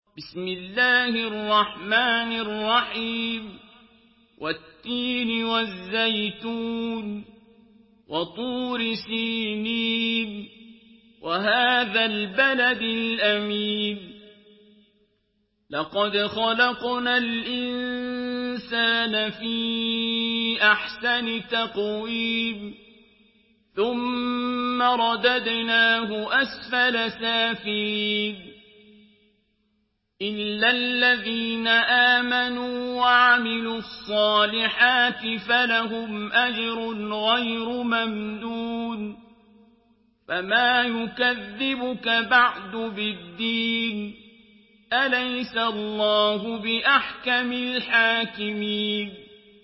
Surah At-Tin MP3 in the Voice of Abdul Basit Abd Alsamad in Hafs Narration
Surah At-Tin MP3 by Abdul Basit Abd Alsamad in Hafs An Asim narration.
Murattal Hafs An Asim